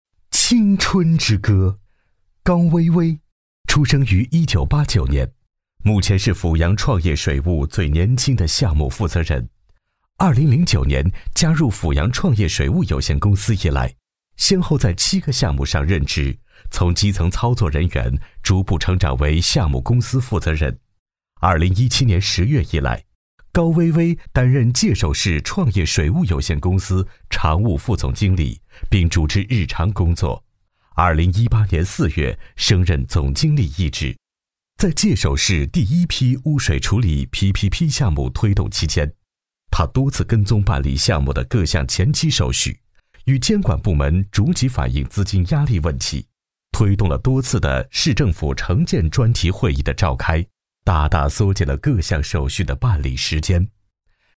稳重磁性 人物专题
品质男中音，高性价比，声音特点，稳重磁性，自然，厚重带点透亮 、底蕴。